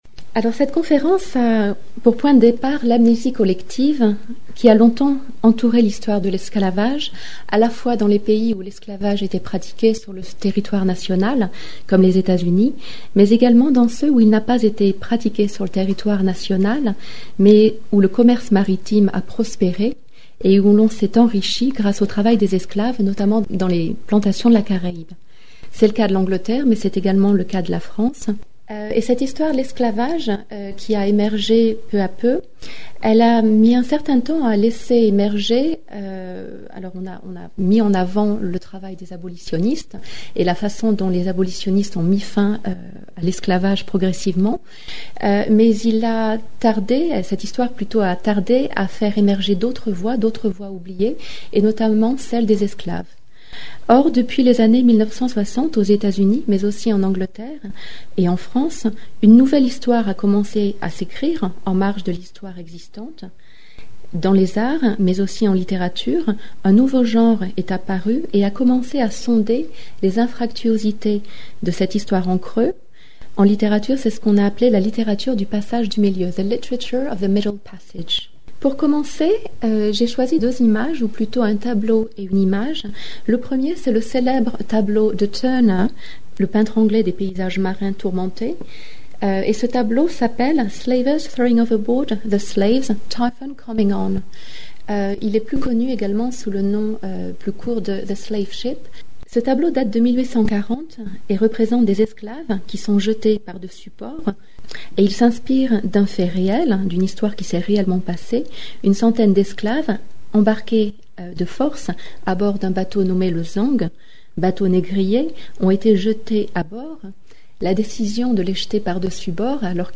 Cette conférence a pour point de départ l’amnésie collective qui a longtemps caractérisé l’histoire de l’esclavage et retrace les étapes de la mise en visibilité des acteurs de l’histoire de l’esclavage.